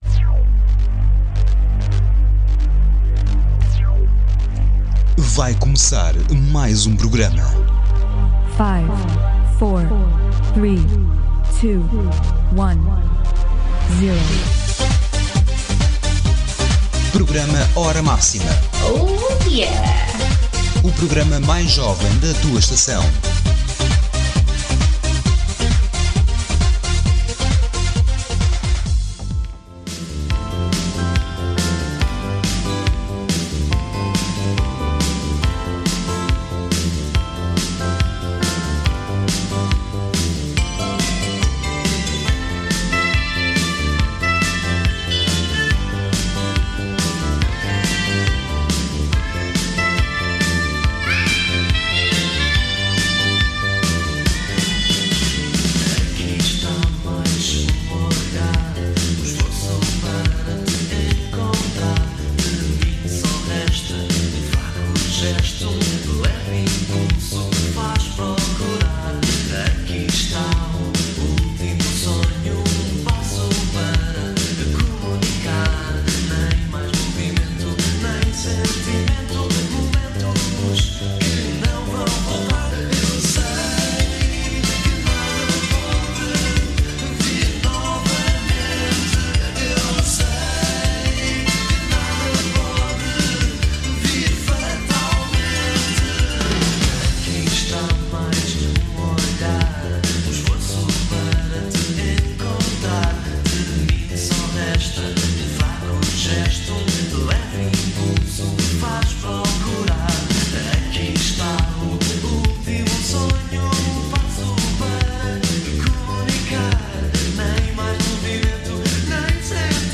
historical rock
traditional-electronic fusion